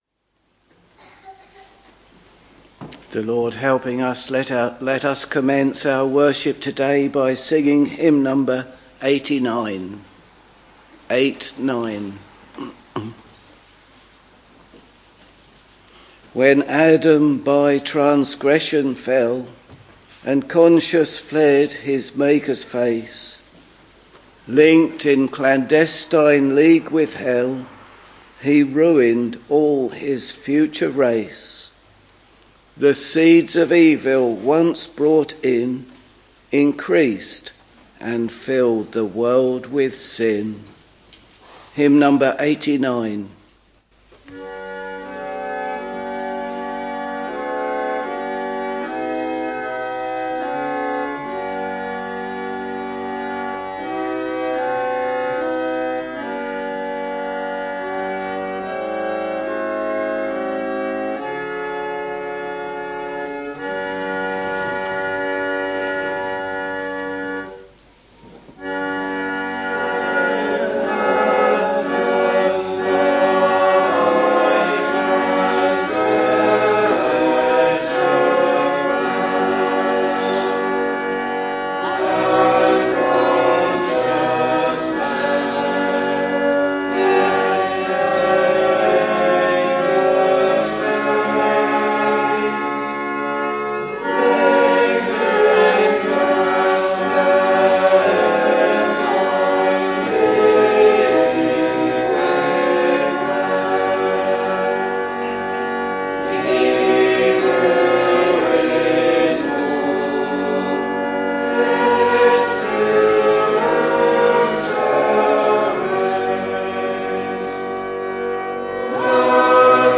Sunday, 8th December 2024 — Morning Service Preacher
Hymns: 89, 634, 403 Reading: Luke 1:1-25, 57- Bible and hymn book details Listen Download File